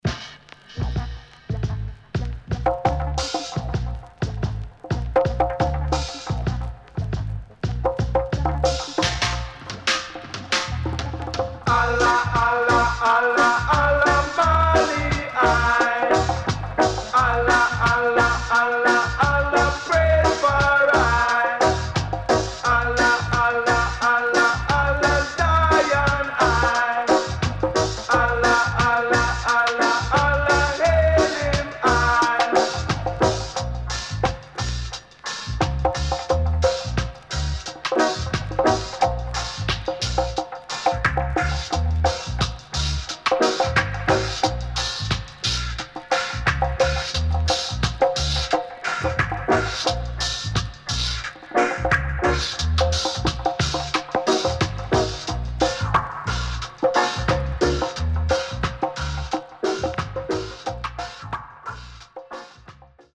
HOME > REISSUE [REGGAE / ROOTS]
Very Rare Killer Roots Rock